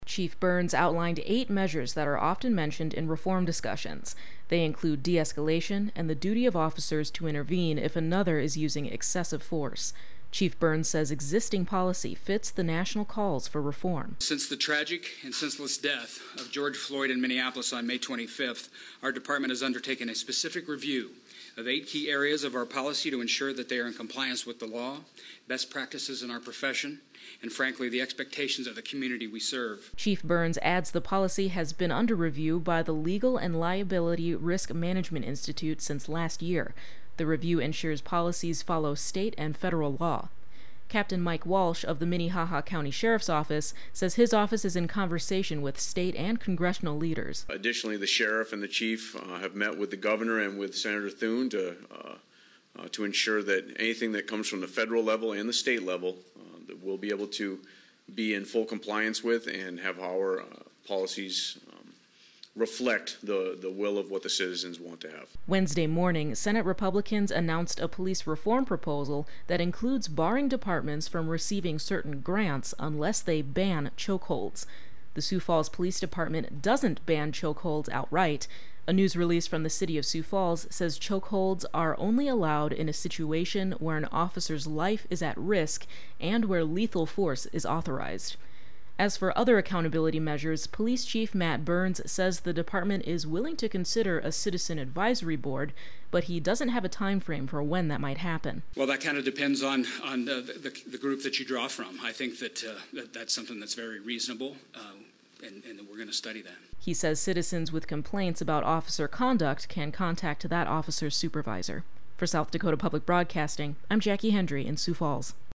Sioux Falls Police Chief Matt Burns during a press conference on Wed.